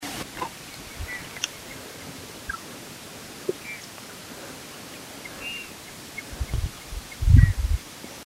Suiriri Flycatcher (Suiriri suiriri)
Location or protected area: Reserva de Biósfera Ñacuñán
Condition: Wild
Certainty: Recorded vocal
suiriri.mp3